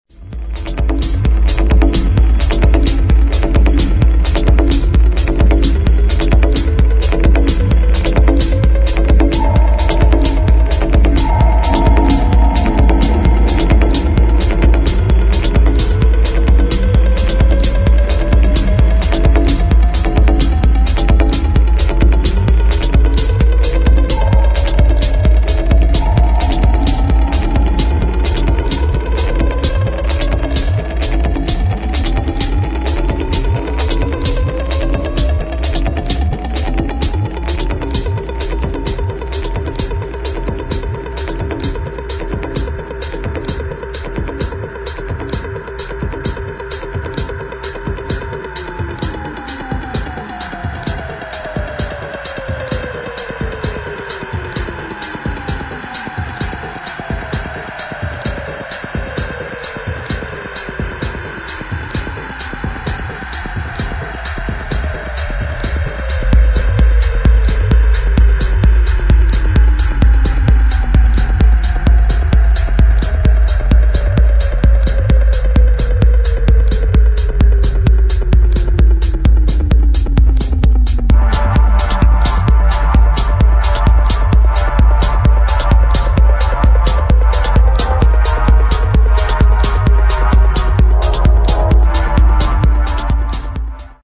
[ TECHNO / BASS ]